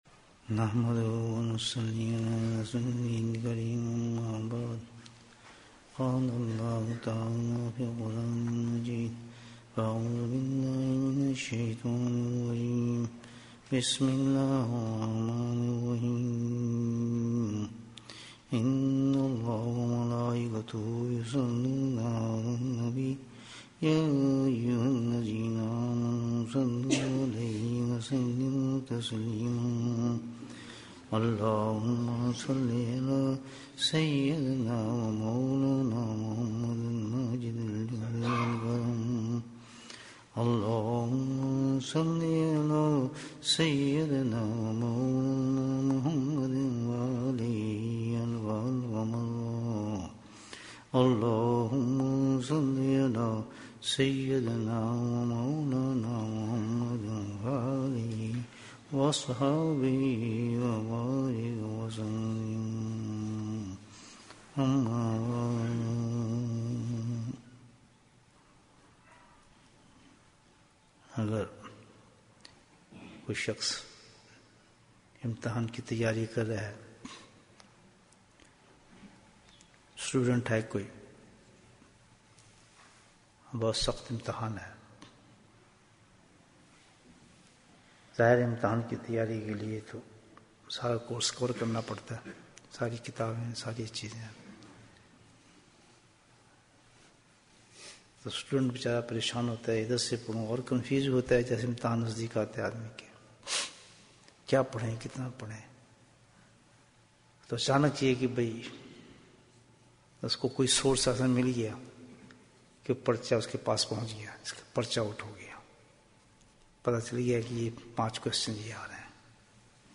Bayan, 48 minutes16th January, 2020